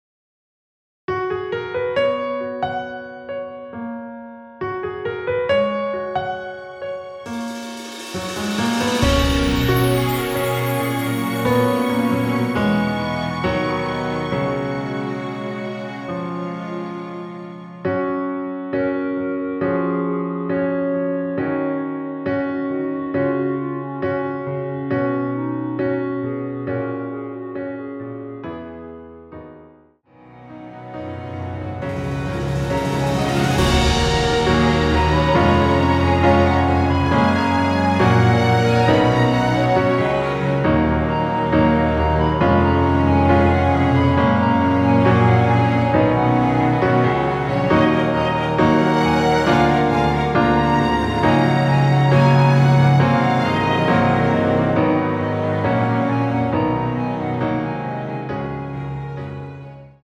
원키에서(-3)내린 멜로디 포함된 MR입니다.(미리듣기 확인)
Gb
앞부분30초, 뒷부분30초씩 편집해서 올려 드리고 있습니다.
중간에 음이 끈어지고 다시 나오는 이유는